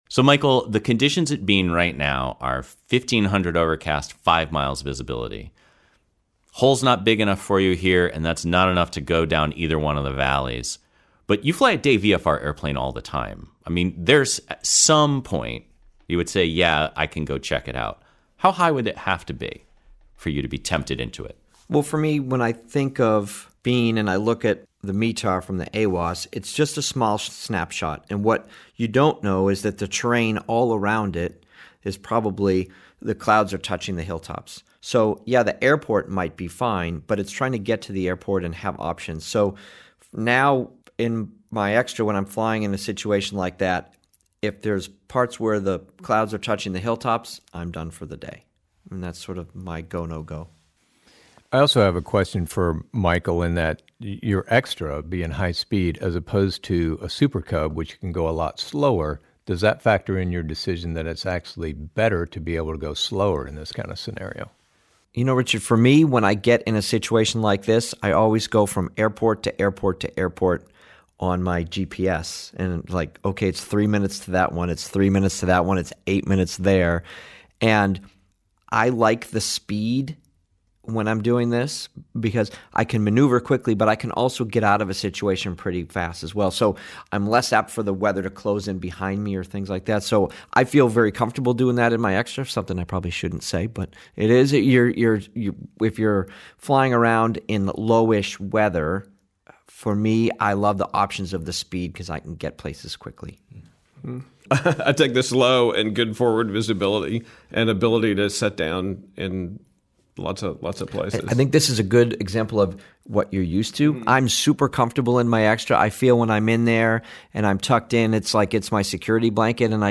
undercast_atbean _roundtable.mp3